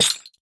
Minecraft Version Minecraft Version latest Latest Release | Latest Snapshot latest / assets / minecraft / sounds / block / chain / break3.ogg Compare With Compare With Latest Release | Latest Snapshot